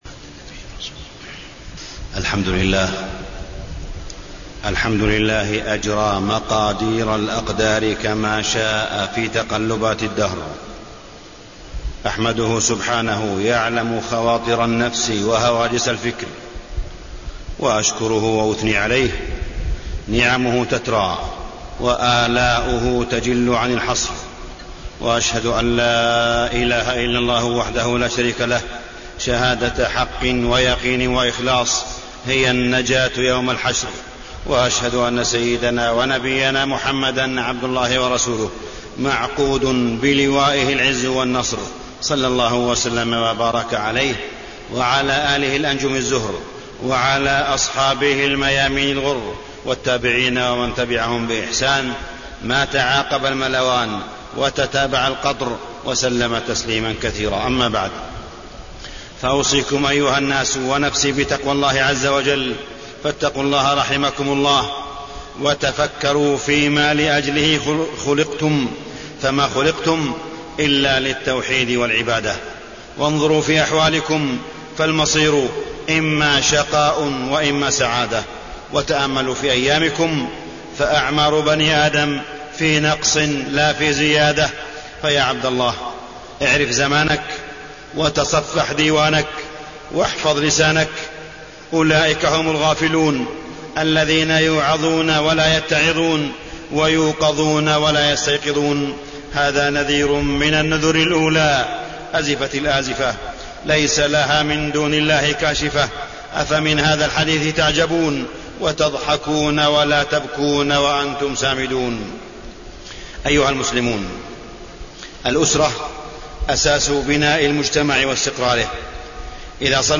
تاريخ النشر ٤ شعبان ١٤٢٨ هـ المكان: المسجد الحرام الشيخ: معالي الشيخ أ.د. صالح بن عبدالله بن حميد معالي الشيخ أ.د. صالح بن عبدالله بن حميد تنبيهات للزوج والزوجة